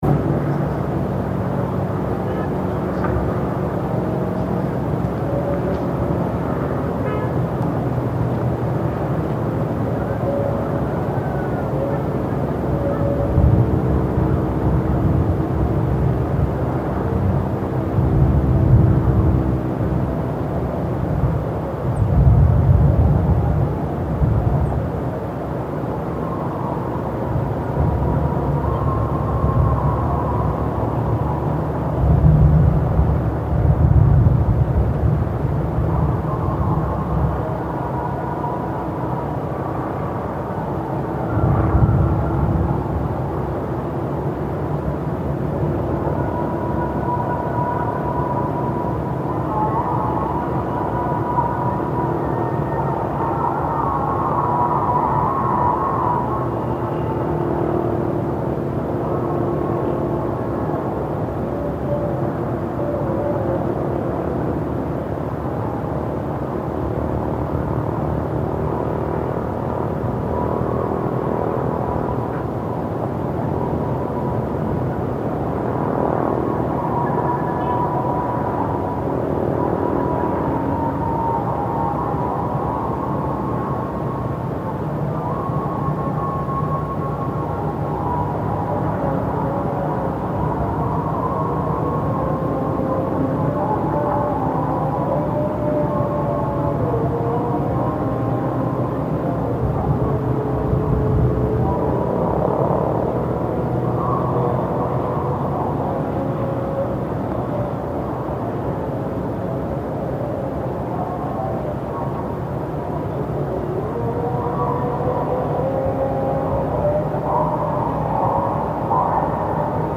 Peaceful ambient sound from the Bamiyan Valley in Afghanistan, post 45 years of war in Afghanistan. Recordings from top of inside Buddha Shah-Mama, where the head used to be.